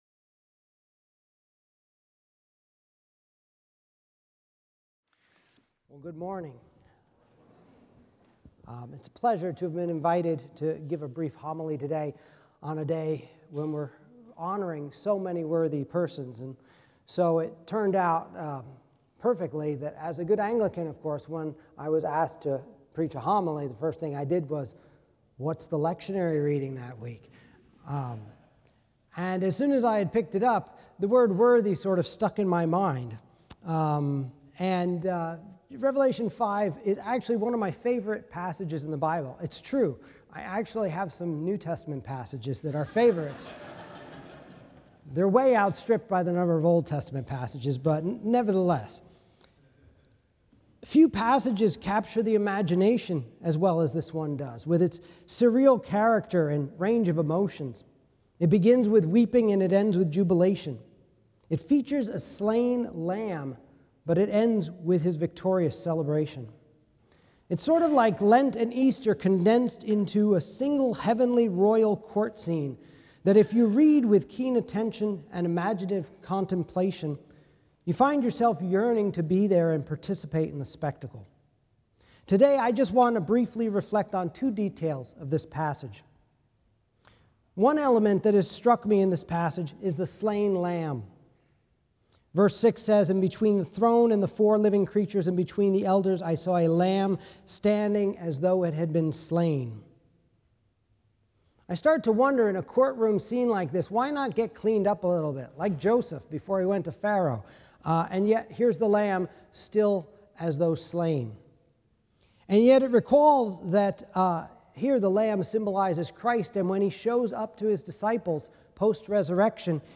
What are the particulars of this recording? recorded on Wednesday, May 7, 2025 at Asbury Theological Seminary's Kentucky campus<